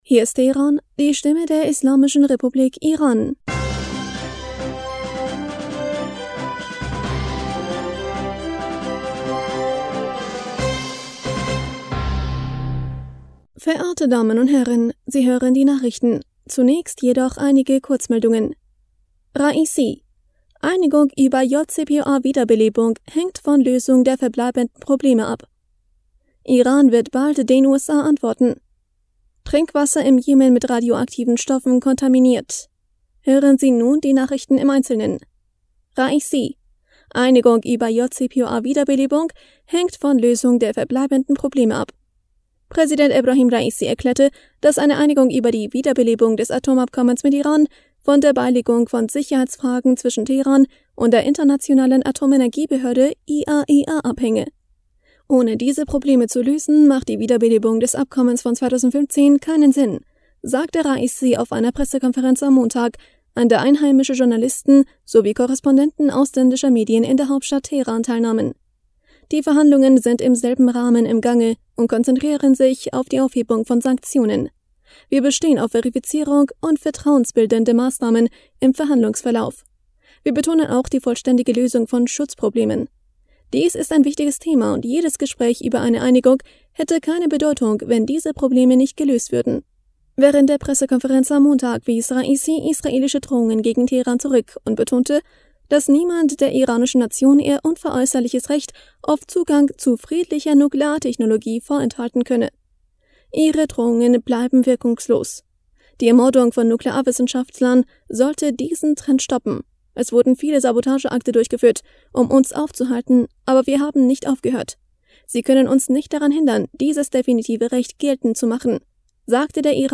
Nachrichten vom 29. August 2022